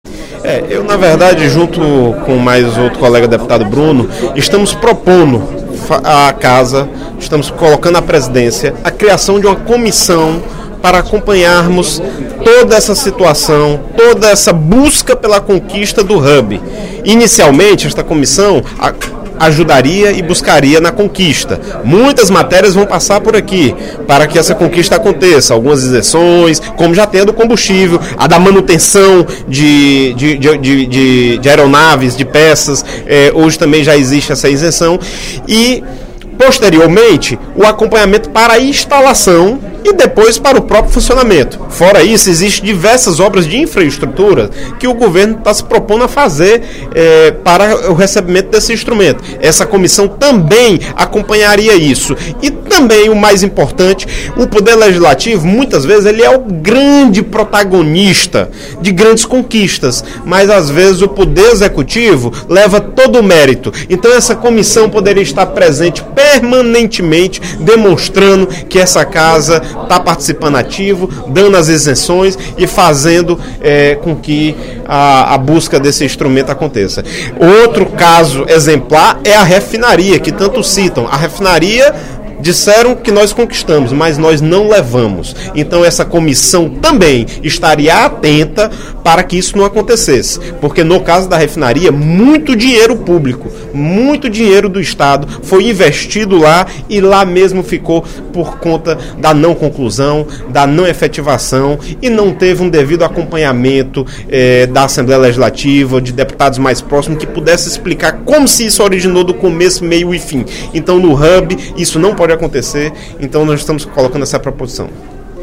O deputado Joaquim Noronha (PP) anunciou, durante o primeiro expediente da sessão plenária desta sexta-feira (26/06), que está trabalhando para a formação de uma comissão especial da Assembleia Legislativa para acompanhar o andamento das tratativas sobre a implantação do Hub da TAM em Fortaleza.